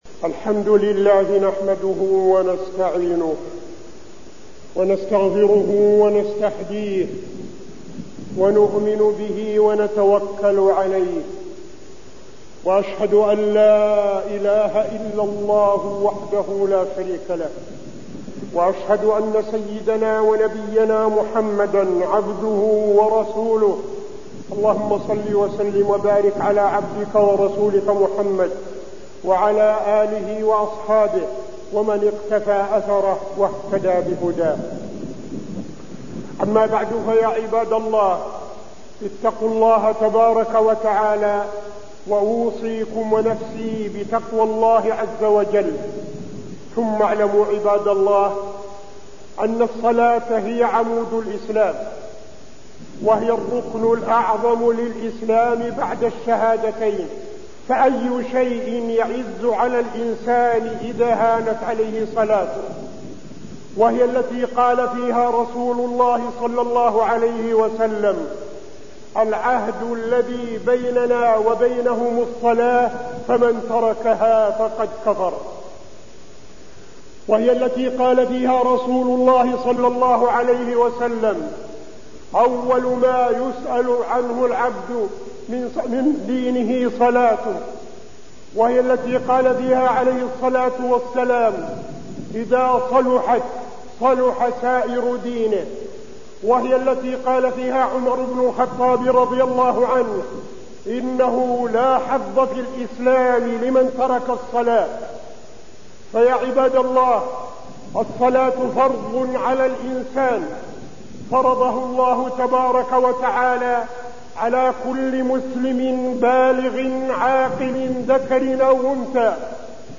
تاريخ النشر ١٣ شوال ١٤٠٦ هـ المكان: المسجد النبوي الشيخ: فضيلة الشيخ عبدالعزيز بن صالح فضيلة الشيخ عبدالعزيز بن صالح الصلاة